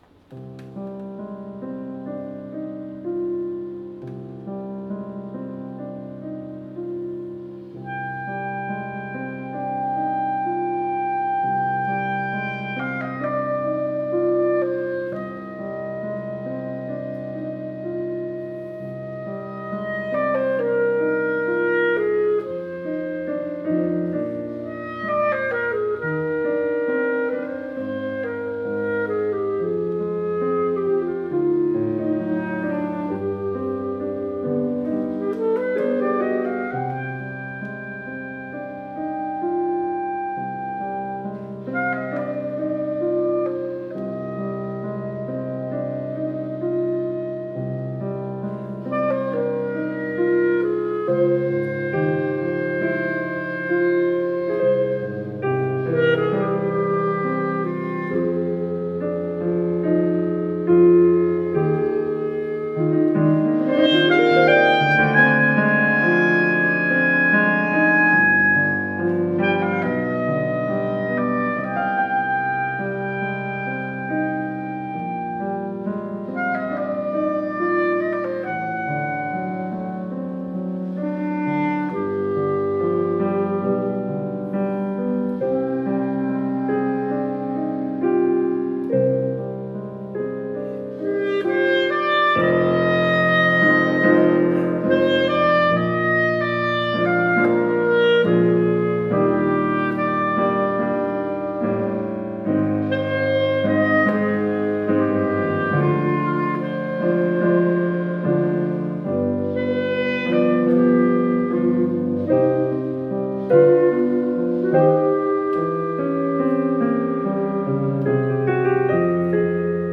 Piano Accompaniment